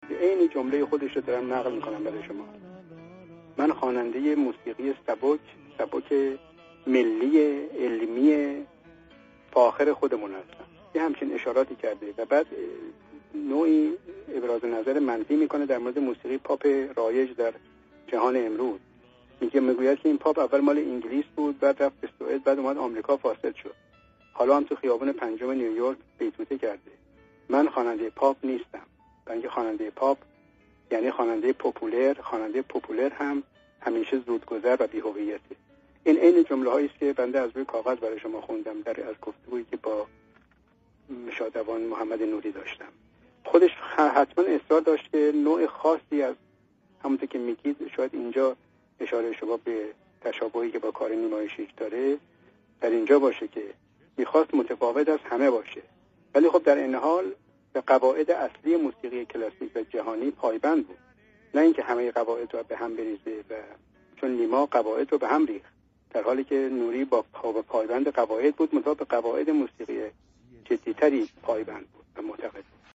Radio Zamaneh Interview